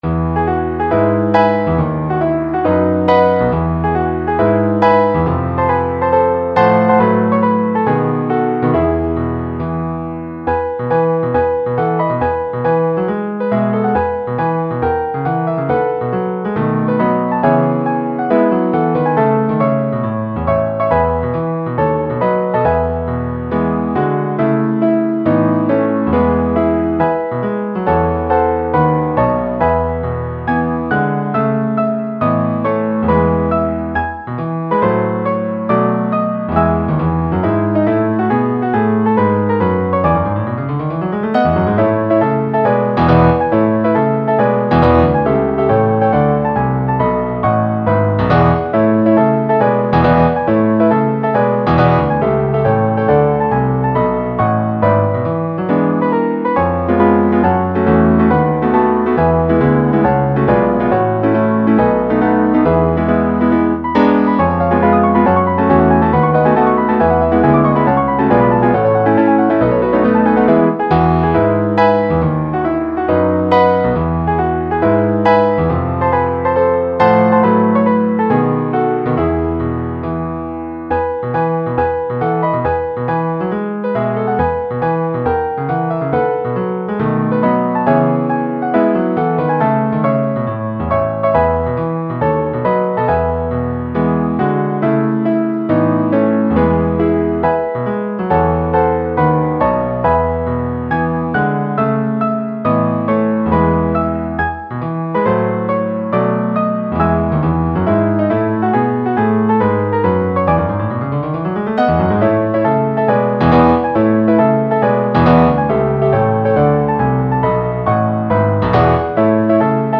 (Base Mp3)
gigio base.mp3